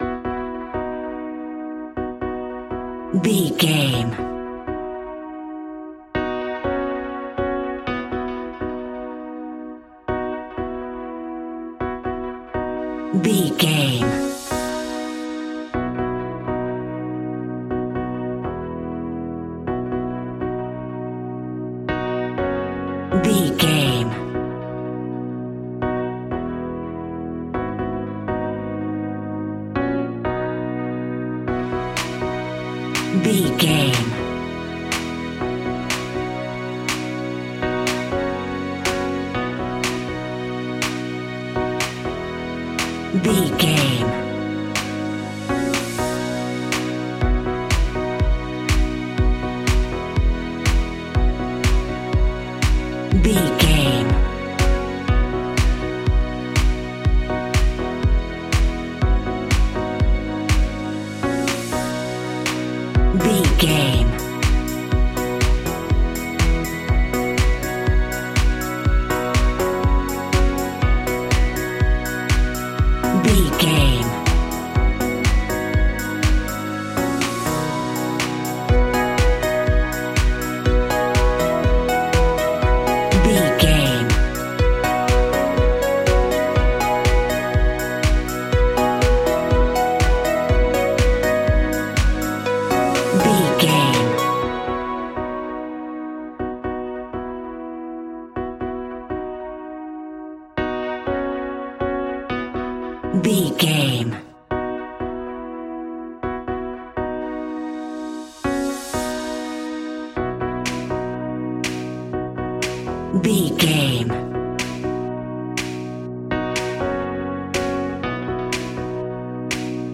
Ionian/Major
groovy
uplifting
driving
energetic
bouncy
synthesiser
drums
drum machine
strings
electric piano
electronic